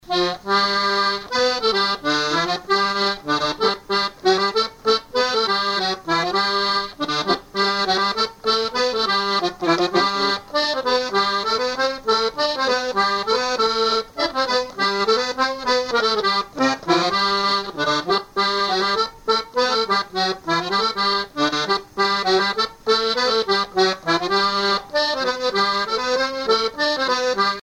Chants brefs - A danser
danse : mazurka
musique à danser à l'accordéon diatonique
Pièce musicale inédite